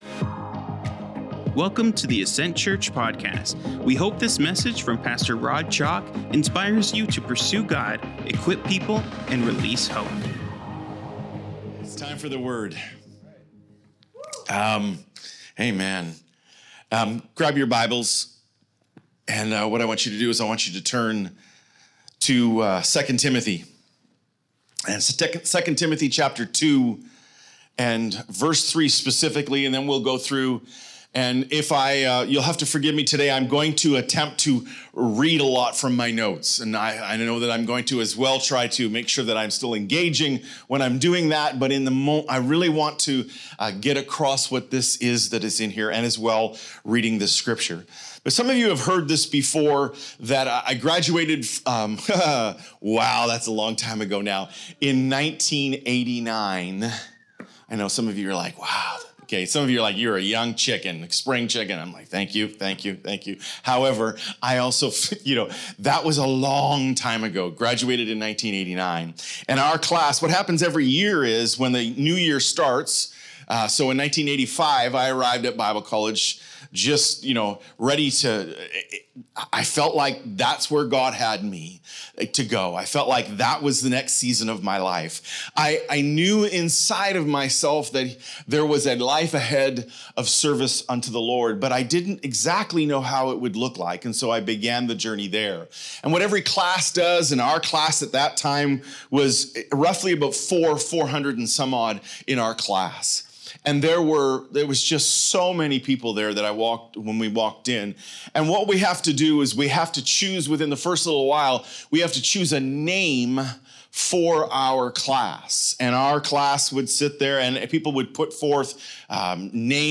Sermons | Ascent Church